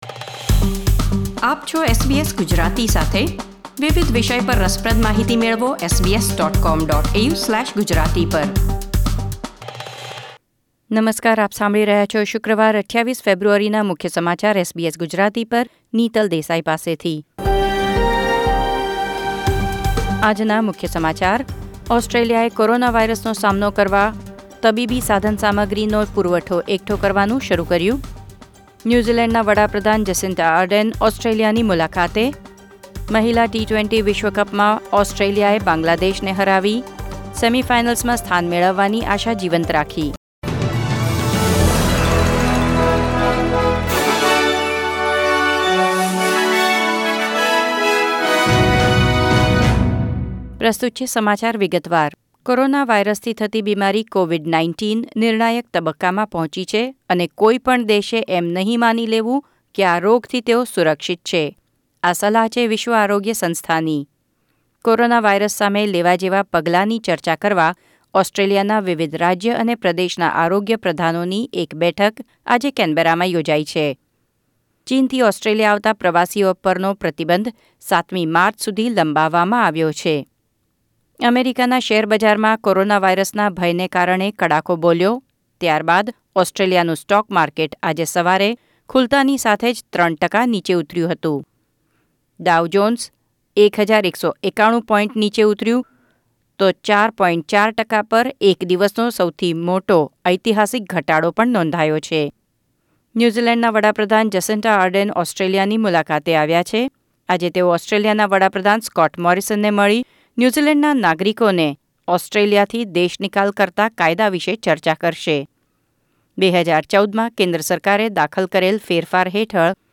SBS Gujarati News Bulletin 28 February 2020